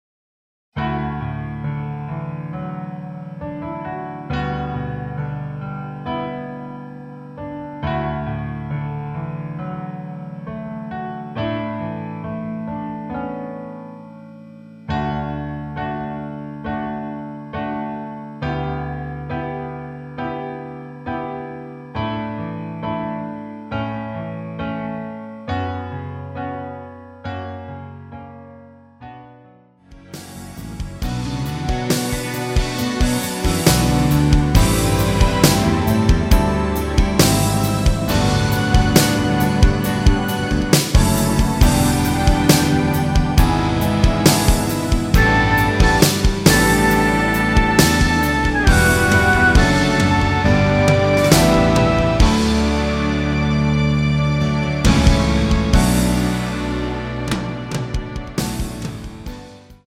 MR 입니다.
앞부분30초, 뒷부분30초씩 편집해서 올려 드리고 있습니다.
중간에 음이 끈어지고 다시 나오는 이유는
곡명 옆 (-1)은 반음 내림, (+1)은 반음 올림 입니다.